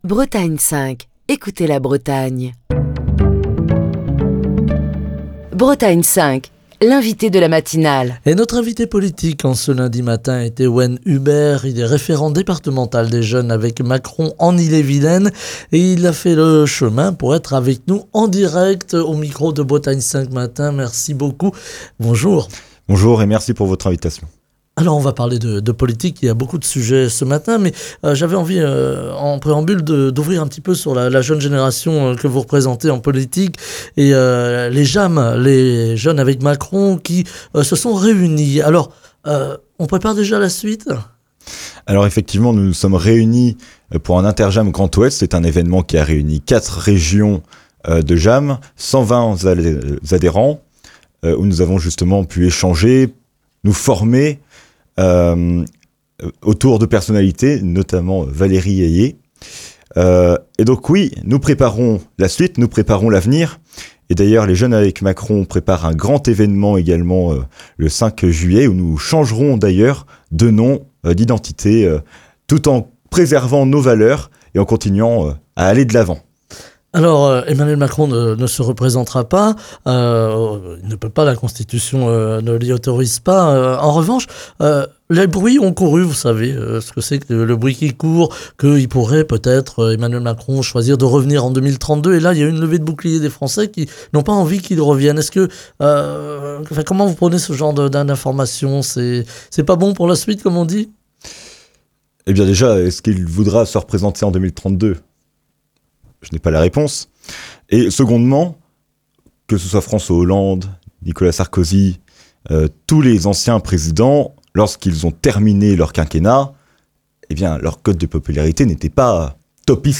était l'invité politique de la matinale de Bretagne 5, ce lundi.